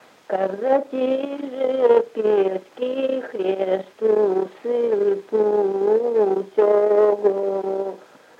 Произношение на месте орфографического «г» заднеязычного смычного /g /, в соответствии с /в/ в литературном языке во флексиях прилагательных и местоимений
/ка-кза-т’ии”-жее п’е-ск’и” хр’е-сту” сыы-пуууу”-чо-го/